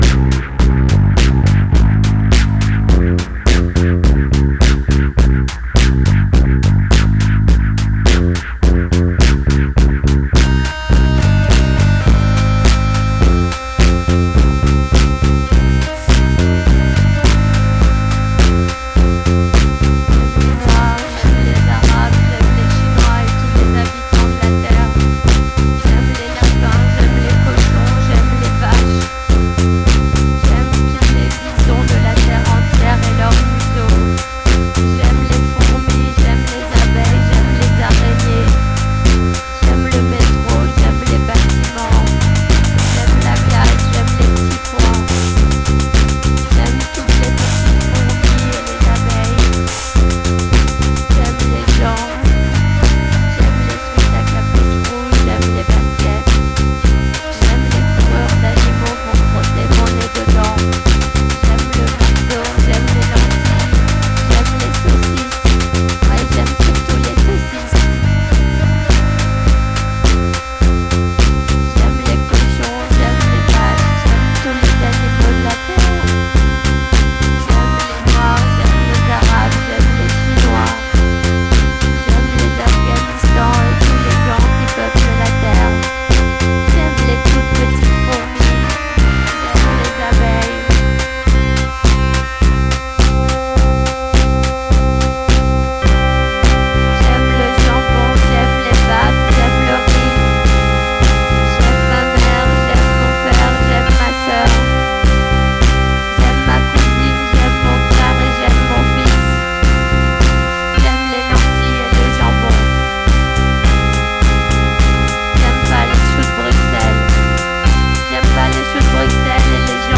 C'est de la cold funk enrouée
C'est mélancolique et ça parle de saucisses